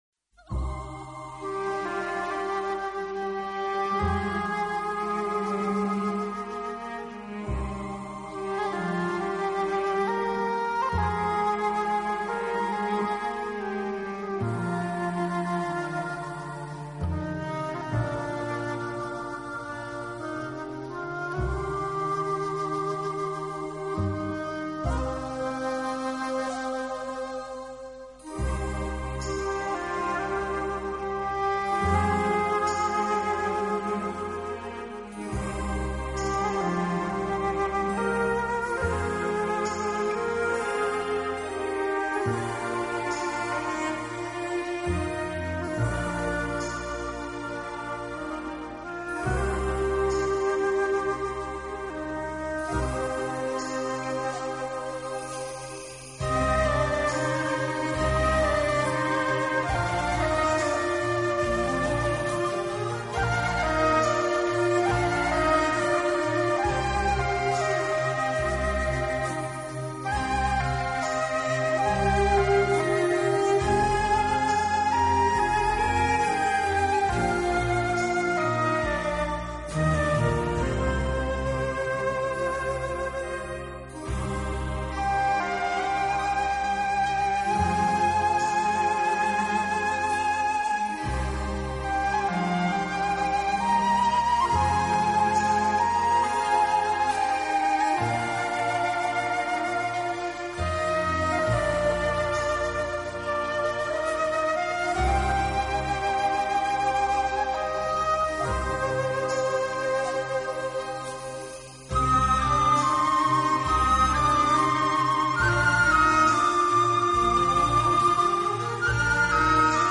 【新世纪纯音乐】
德国低音长笛缓缓如月如水，柔柔地划过停止的思维
流淌的音乐带着落寂找寻失去的天空，笛音悠长，绵绵不绝于耳，长